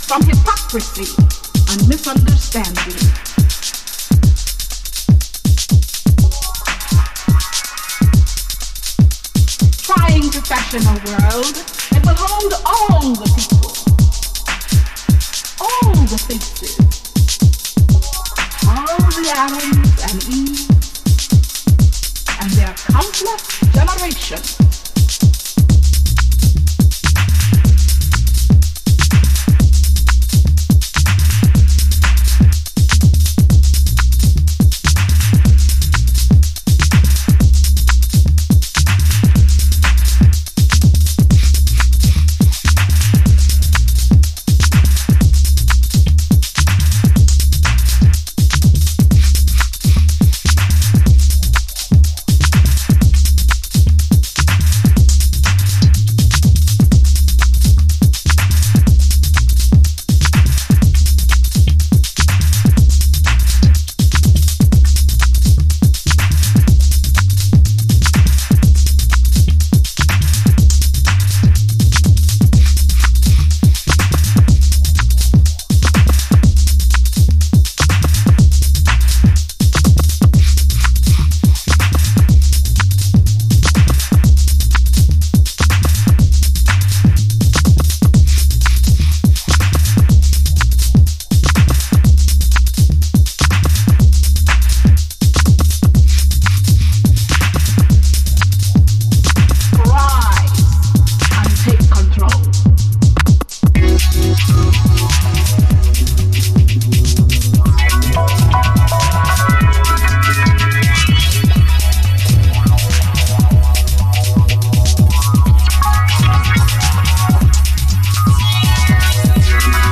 House / Techno
低いところ露骨にウネルベースラインとトリッピーなシンセワークで淡々とグルーヴしてくれるタフで頼りになるハウス。